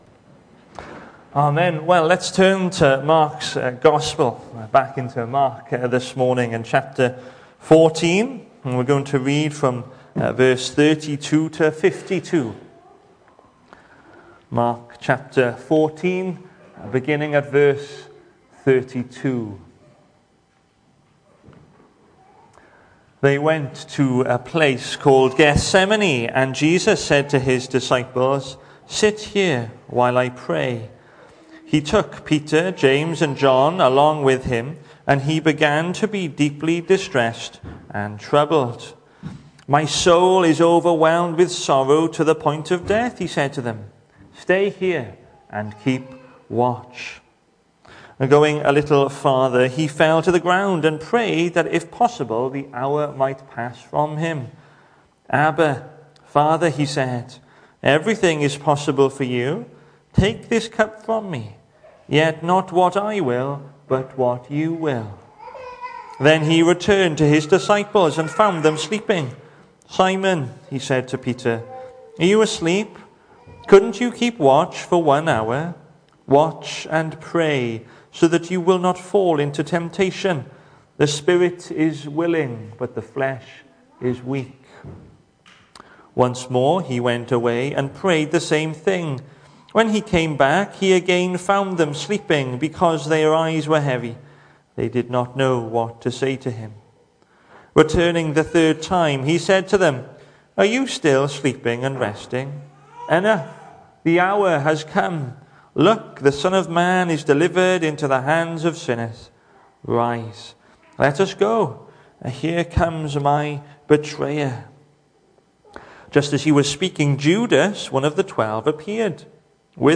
The 18th of January saw us host our Sunday morning service from the church building, with a livestream available via Facebook.